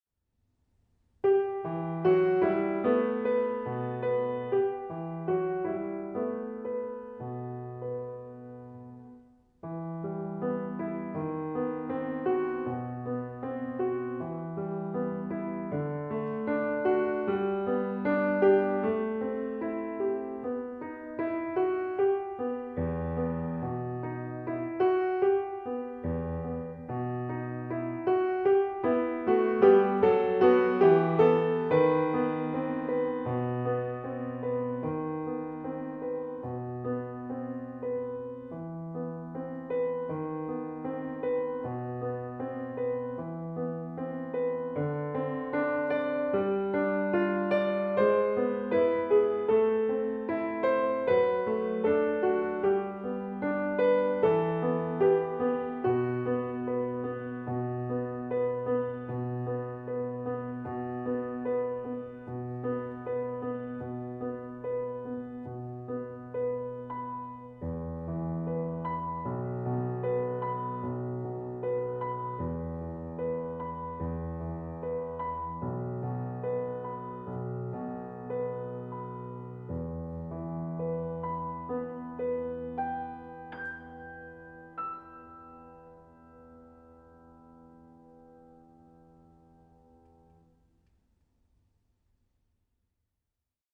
Genre :  Chanson
Style :  Avec accompagnement
Enregistrement piano